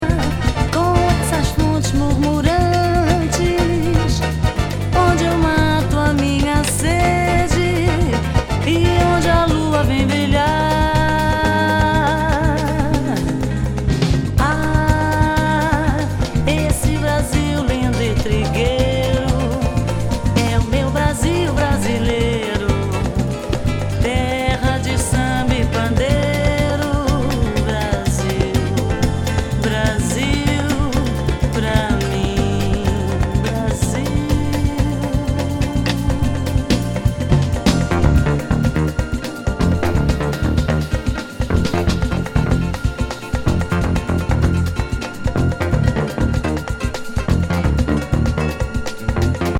サックス奏者81年リーダー作。女性ボーカル入りの極上ライト・メロウ・サンバ